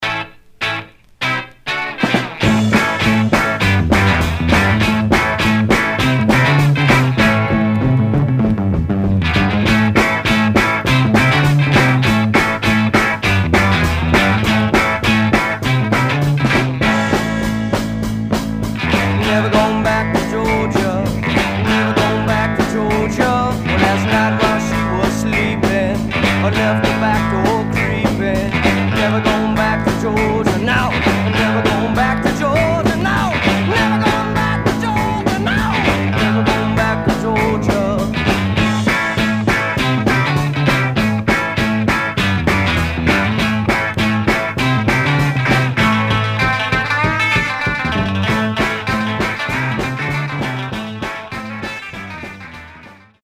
Stereo/mono Mono
Garage, 60's Punk ..........👈🏼 Condition